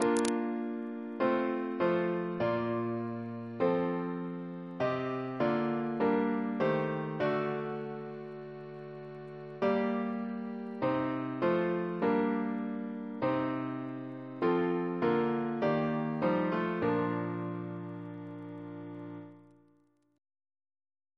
Double chant in B♭ Composer